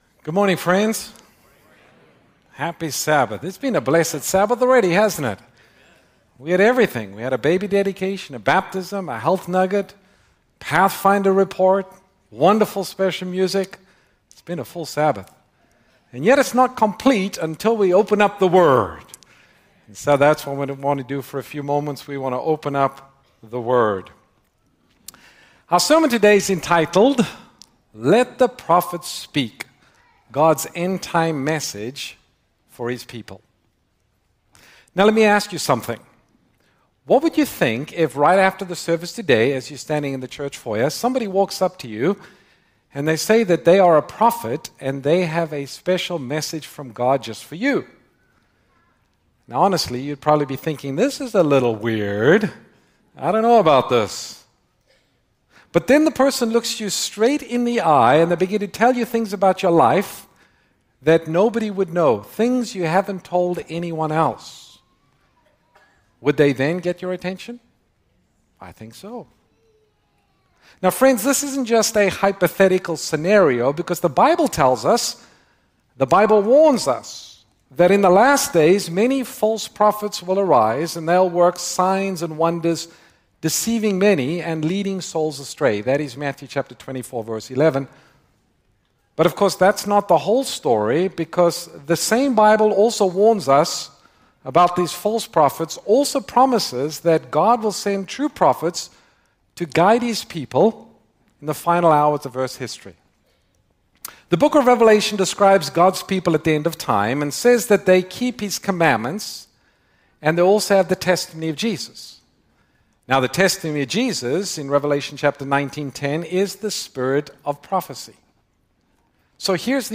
Granite Bay SDA Church